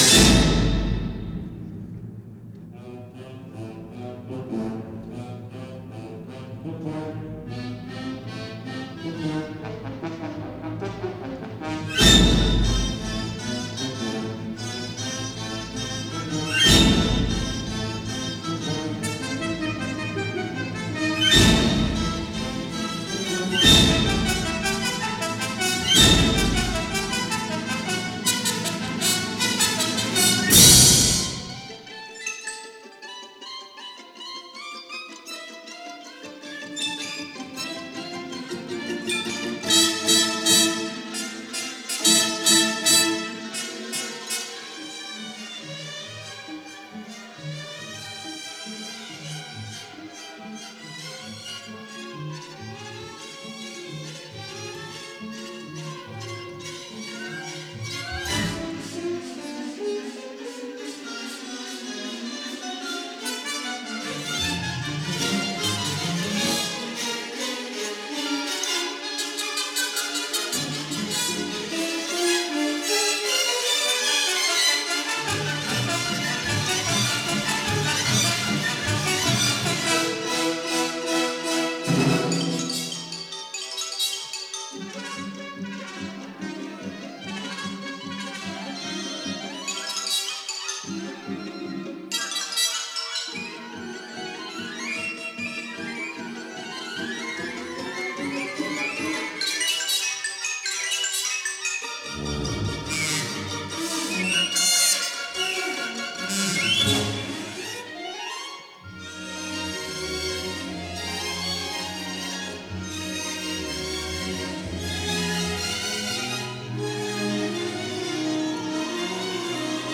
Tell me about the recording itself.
Recorded May 1955 at Victoria Hall, Geneva